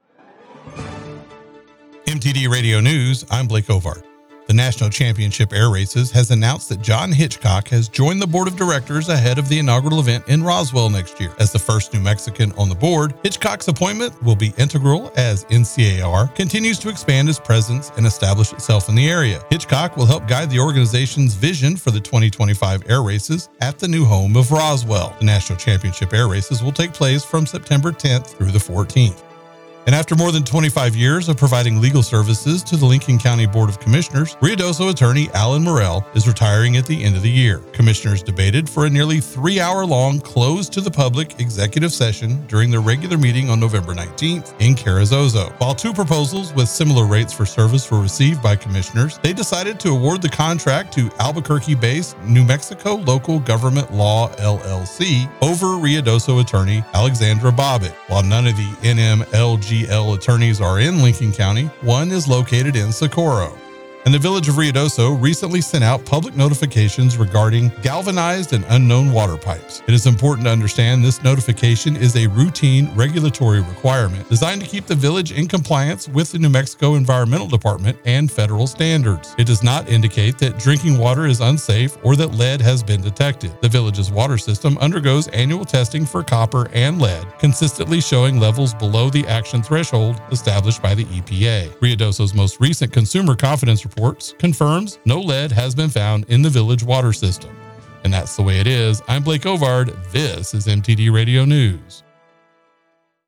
Mix 96.7 NEWS RUIDOSO AND LINCOLN COUNTY